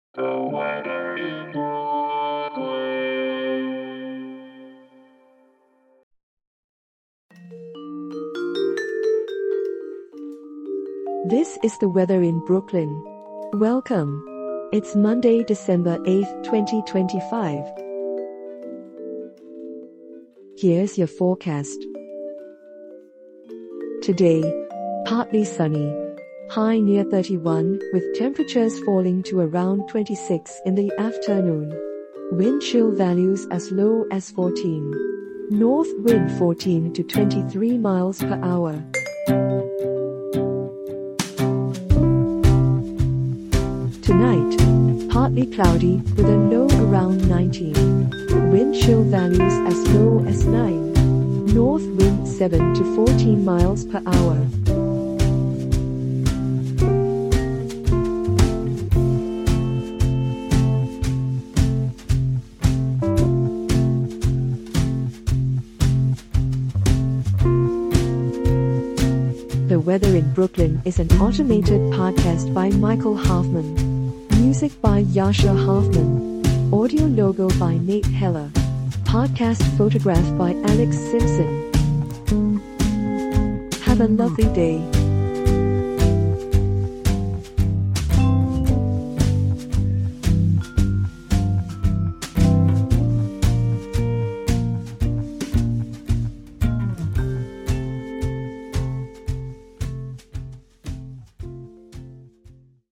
An automated podcast bringing you your daily weather forecast for Brooklyn, NY.